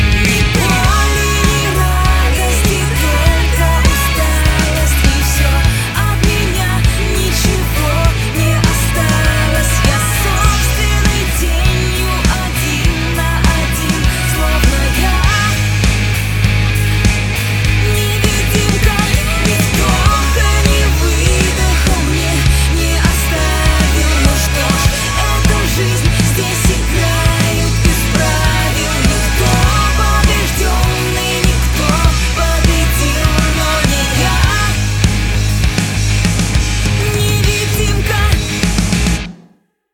• Качество: 192, Stereo
Слегка попсовая песня